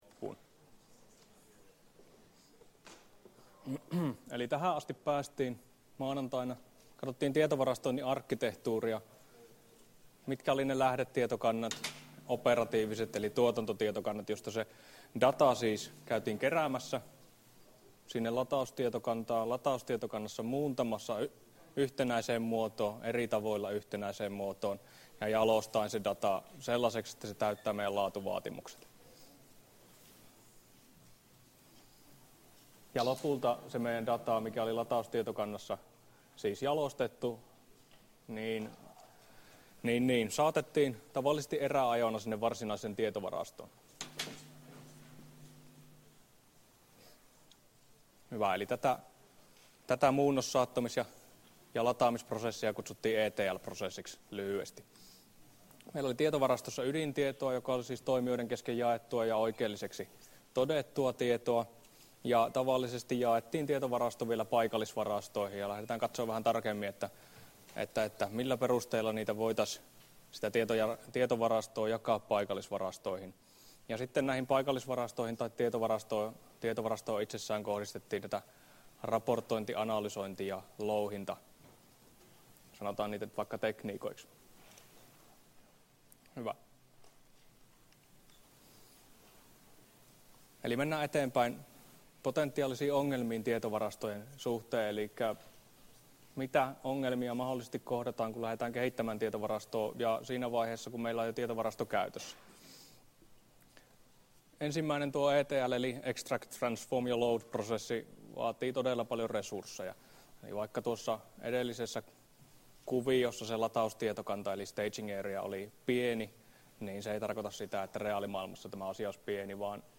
Luento 14 — Moniviestin